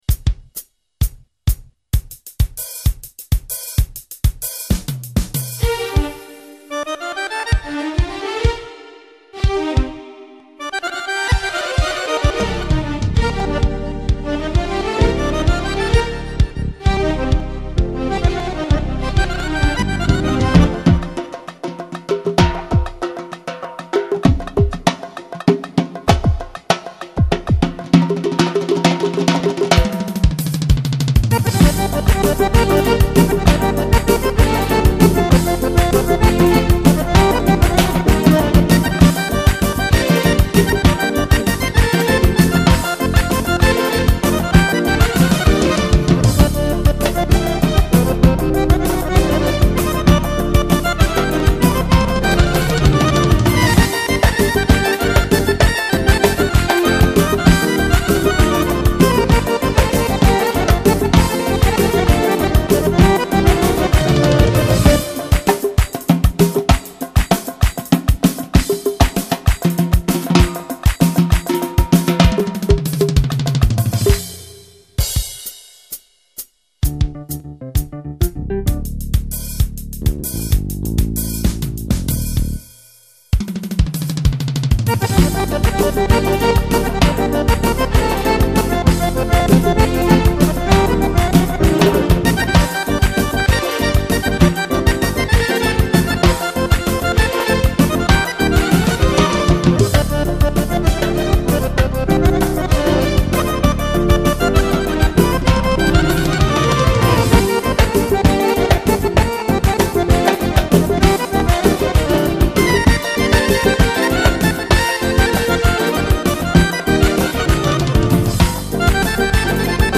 دانلود آهنگ لزگی رقص پا تند بی کلام
دانلود آهنگ شاد لزگی آذری برای رقص پا تند و بی کلام